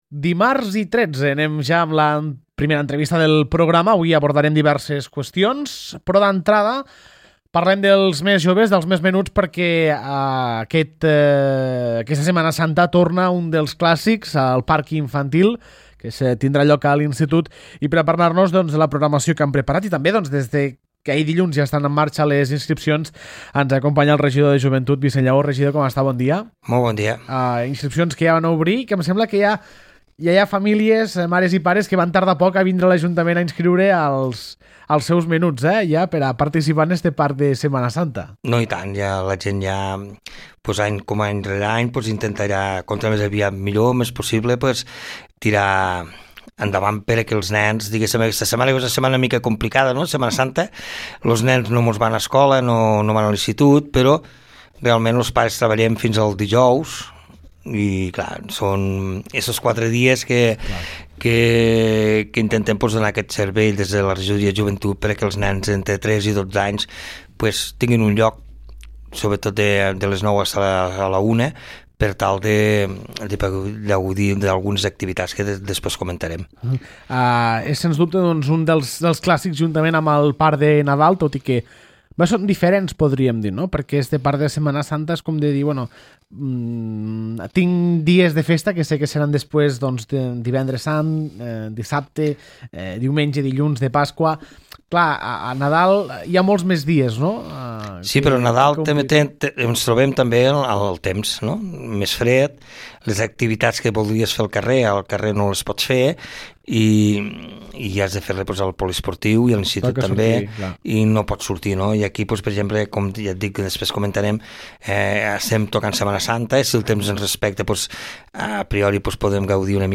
Els més menuts de la població poden gaudir d’una Setmana Santa diferent amb el Parc Infantil que organitza la regidoria de Joventut. Ens explica totes les activitats programades, el seu regidor Vicenç Llaó.
Vicenç Llaó, regidor de Joventut